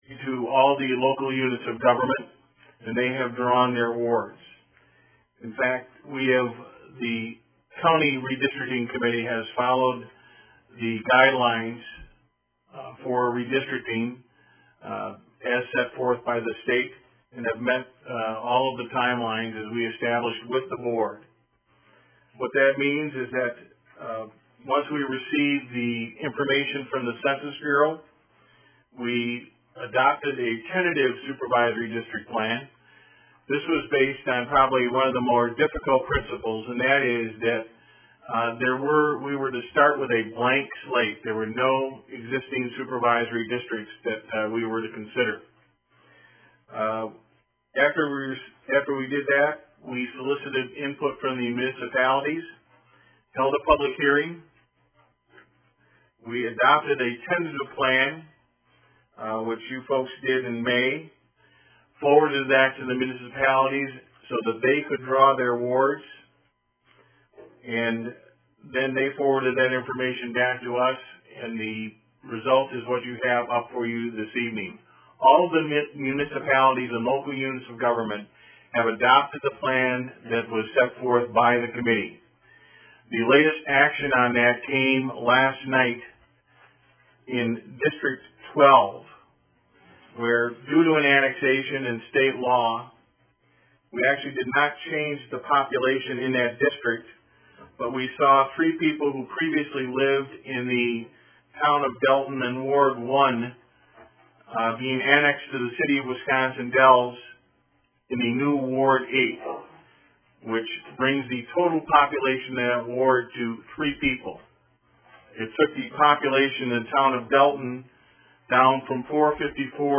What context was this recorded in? September 20, 2011 Sauk County Board of Supervisors meeting agenda (35 KB) September 20, 2011 Sauk County Board of Supervisors meeting AMENDED agenda (53 KB) mp3 audio of September 20, 2011 Sauk County Board of Supervisors meeting (13 MB)